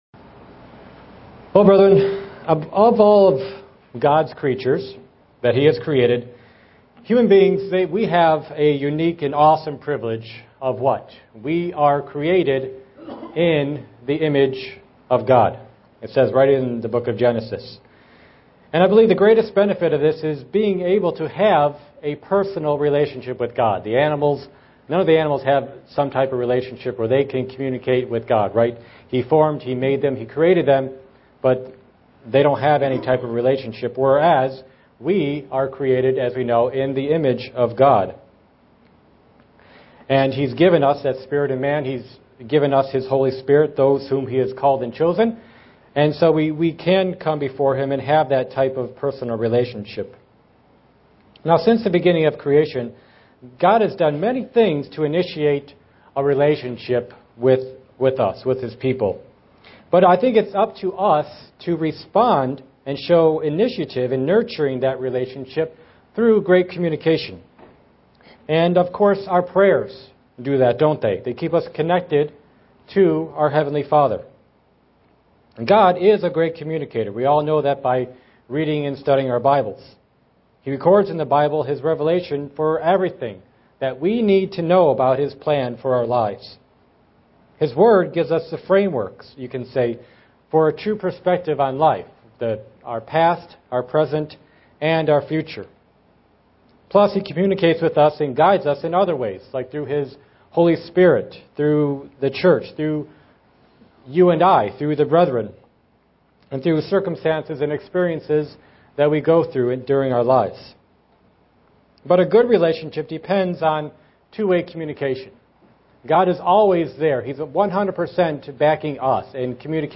Print Seven conditions of answered prayers SEE VIDEO BELOW UCG Sermon Studying the bible?
Given in Buffalo, NY